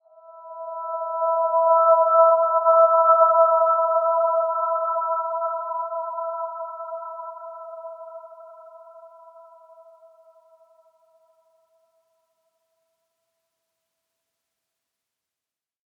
Dreamy-Fifths-E5-f.wav